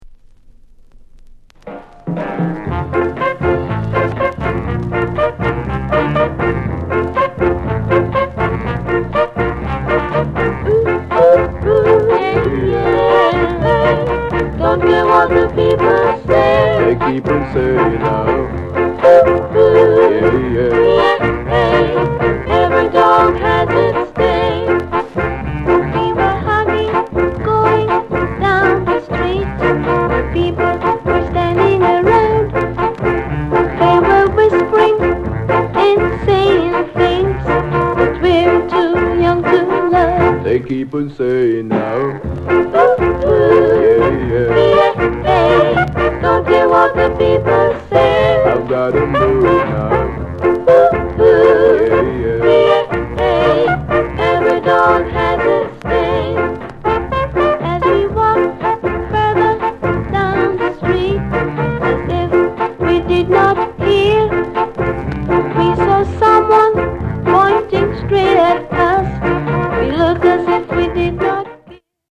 SOUND CONDITION A SIDE VG(OK)
NICE SKA